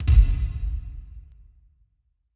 Soundfile 9: a six-channel file. A mono sound, reverberated into 6 channels.
(The direct sound is written to channels 1 and 2 only)
sixchan.wav